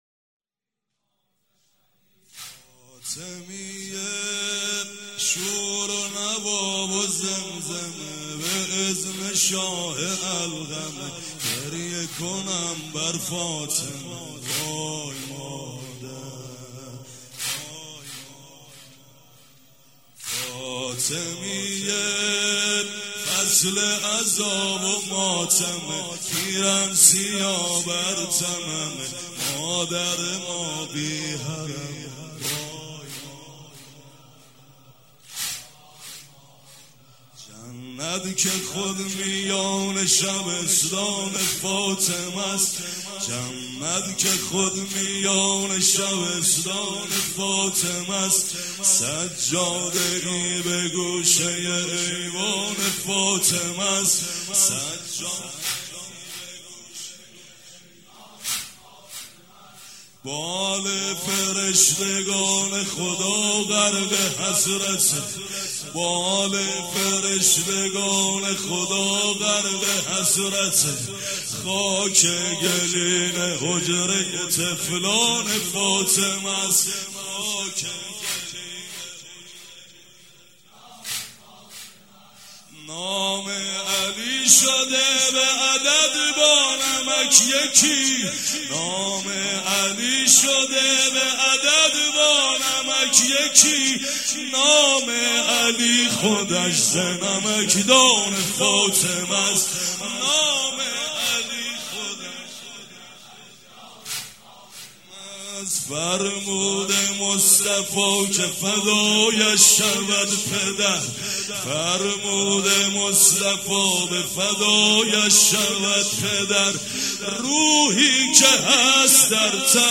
فاطمیه شور و نوا و زمزمه
زمینه، روضه، مناجات